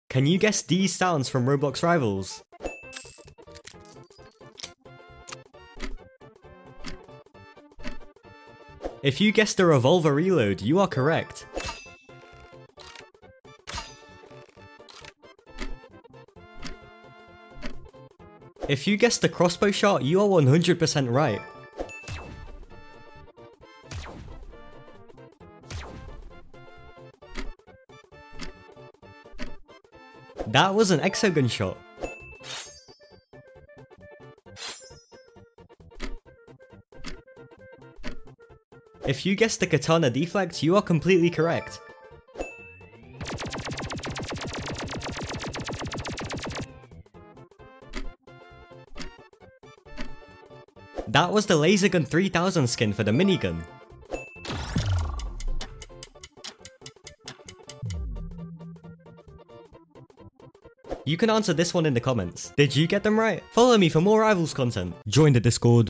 GUESS THE ROBLOX RIVALS SOUNDS sound effects free download